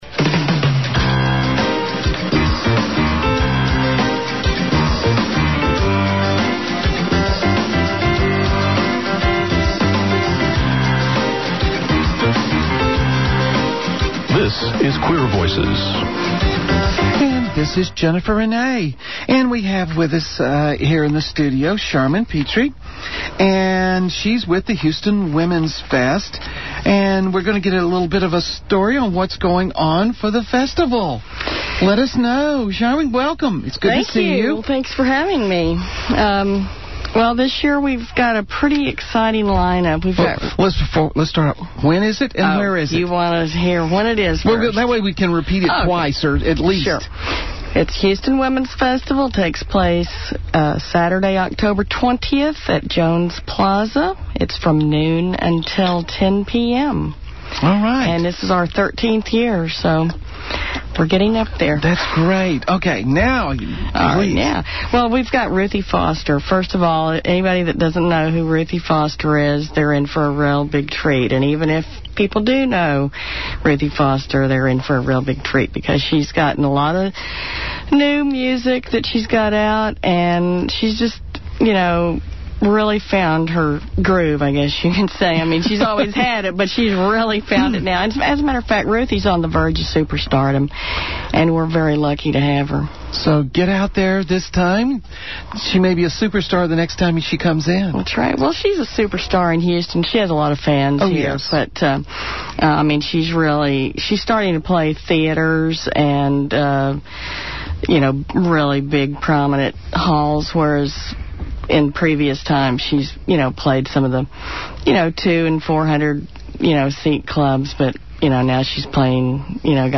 Hwf 2007 Interview On Queer Voices
HWF-2007 interview on queer voices.mp3